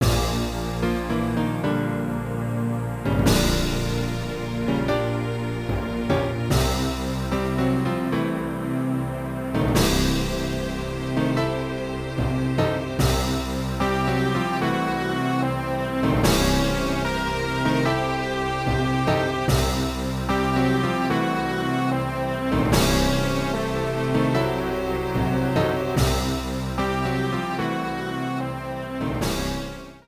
Ripped from game data, then trimmed in Audacity
Fair use music sample